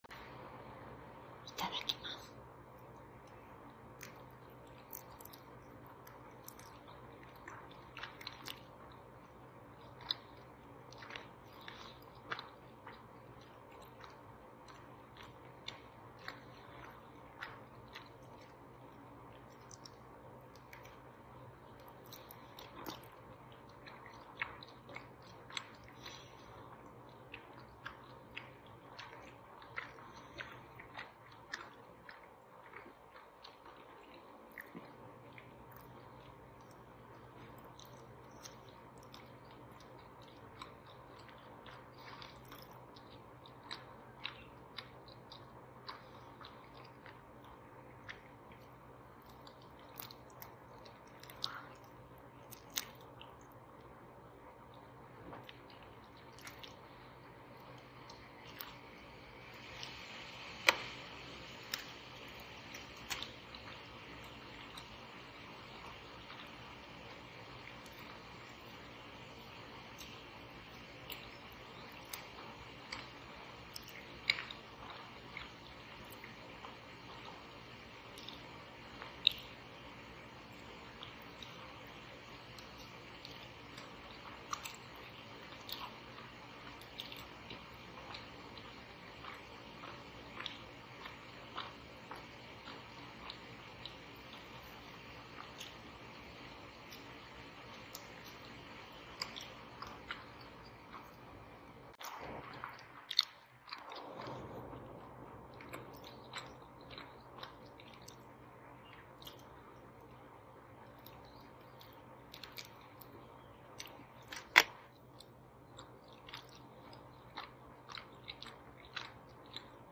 みたらし団子咀嚼音【リクエスト】